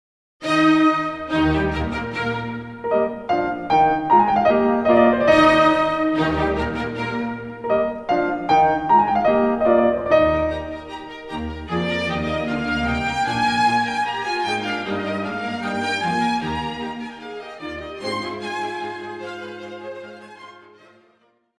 What he does, is that he lets the soloist enter right away, after the orchestra plays a simple chord.
In a piano concerto in the same key, with much the same character in the first movement, Mozart is the one that comes up with this solution.